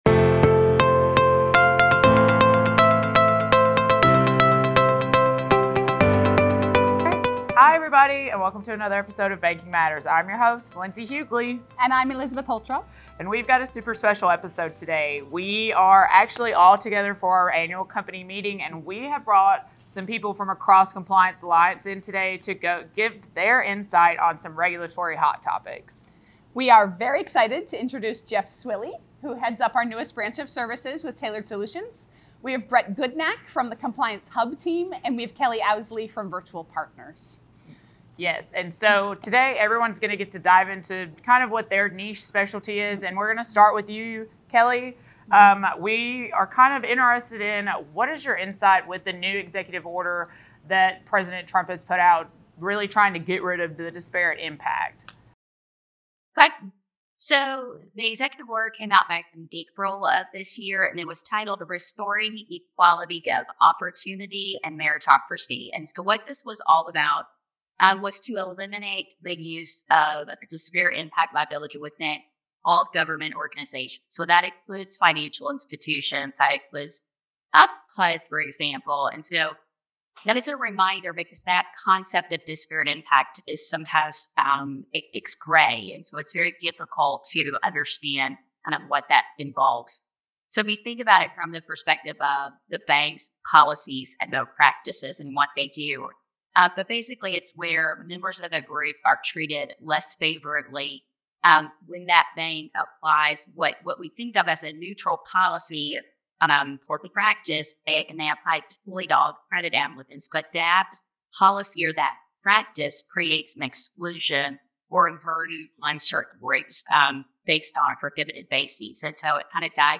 Episode 110 Banking Matters Regulation Hot Topics: A Compliance Alliance Panel Discussion